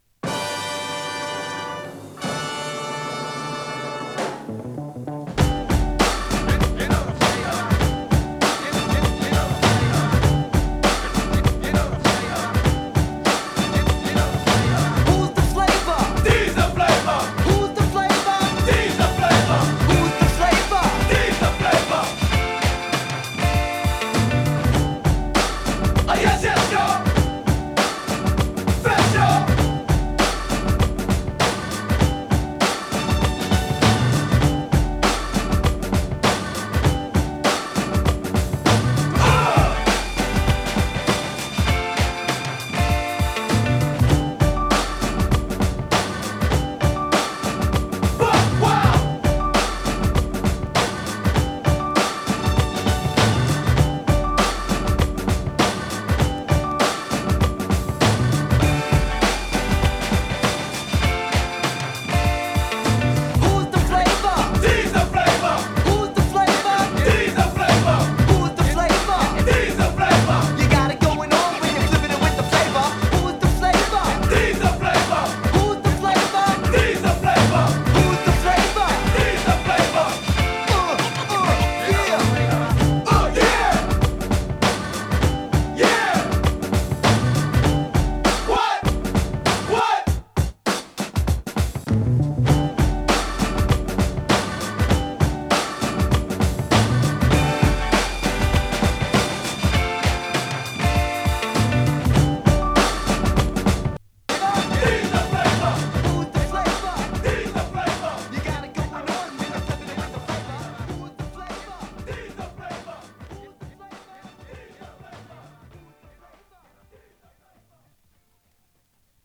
Instrumental 3.48